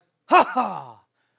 su_cheer2.wav